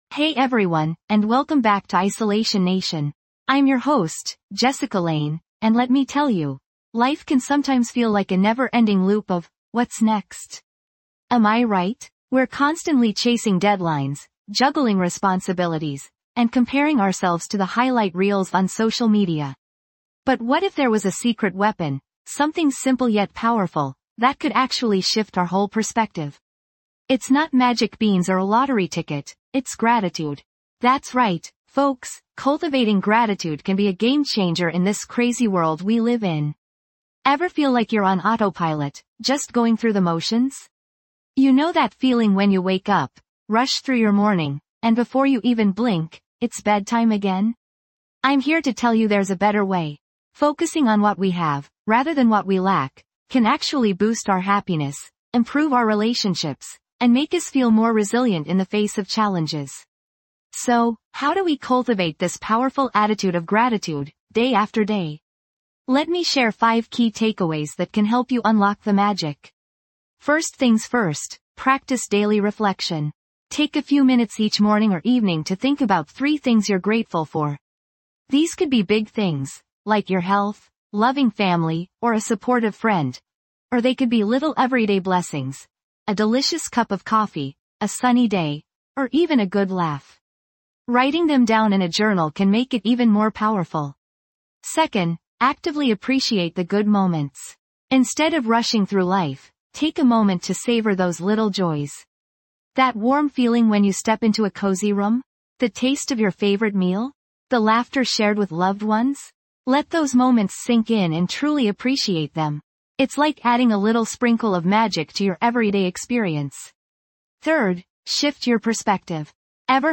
"Isolation Nation | Work, Life And Loneliness" explores the growing epidemic of loneliness and its impact on our work, relationships, and overall well-being. Through intimate conversations with individuals facing similar challenges, we delve into the complexities of modern life and seek ways to build meaningful connections in a fragmented world.